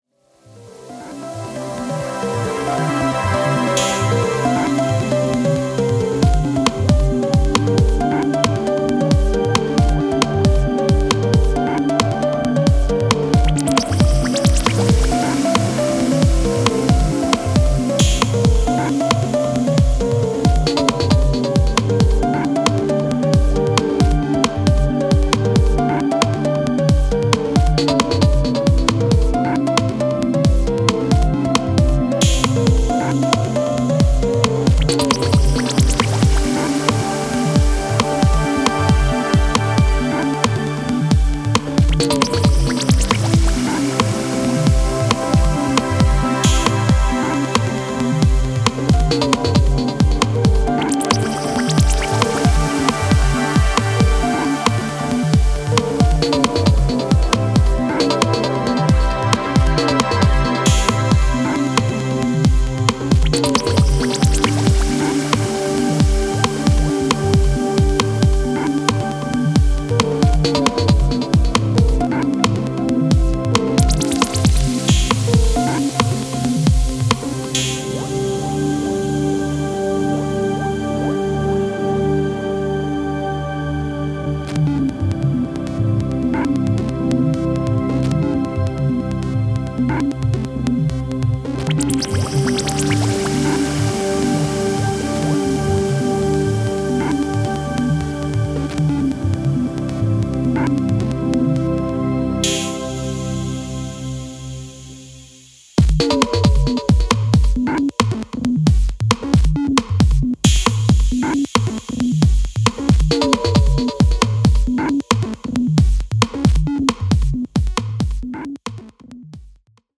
esoteric electronica and transcendental dance
Electro Techno Ambient